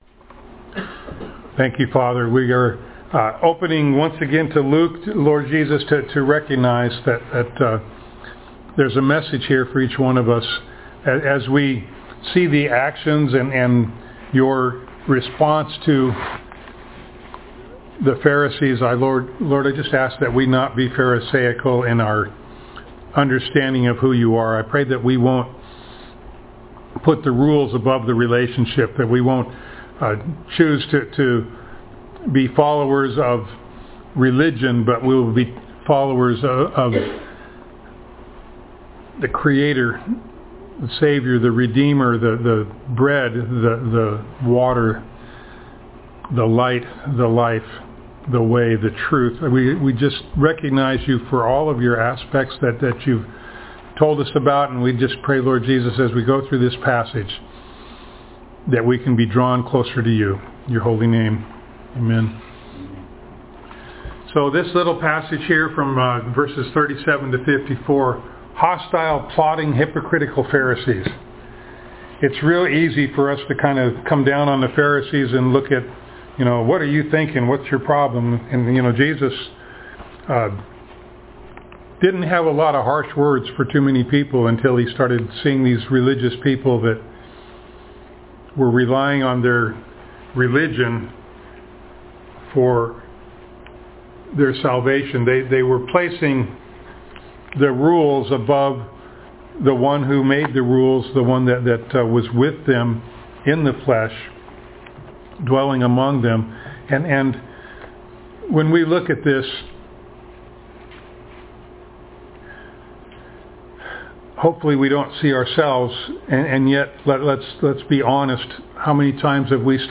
Luke Passage: Luke 11:37-54 Service Type: Sunday Morning Download Files Notes « One Thing is Necessary Warnings